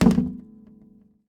Added the eris vending machine sound (I liked it more than tg's, fight me).
machine_vend.ogg